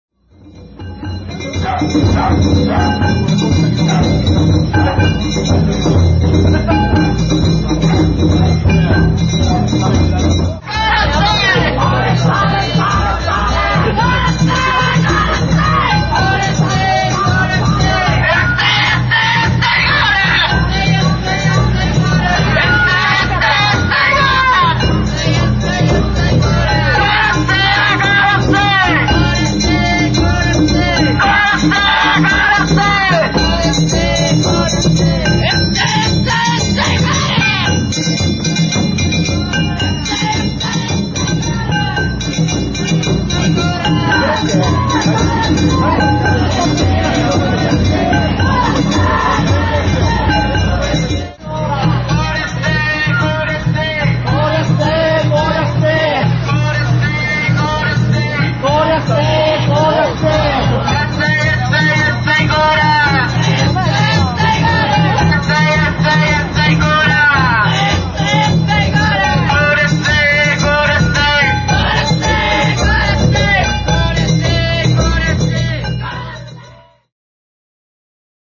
平成２８年６月１２日、藤井寺市の沢田地車お披露目曳行を見に行ってきました。
お囃子無しなので、声の指示がよく聞こえます。
声が飛び交います。